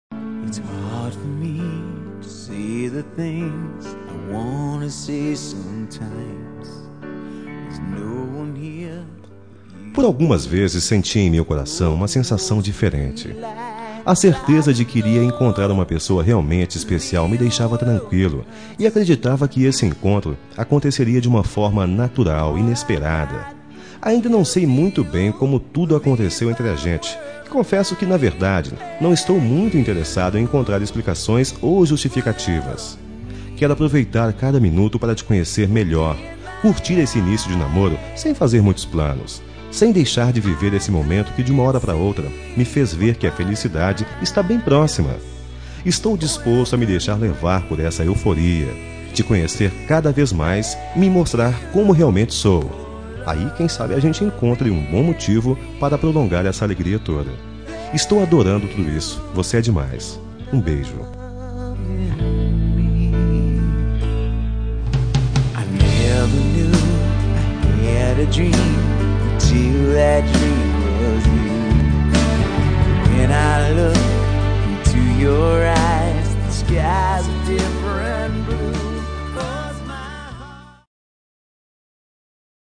Telemensagem Início de Namoro – Voz Masculina – Cód: 758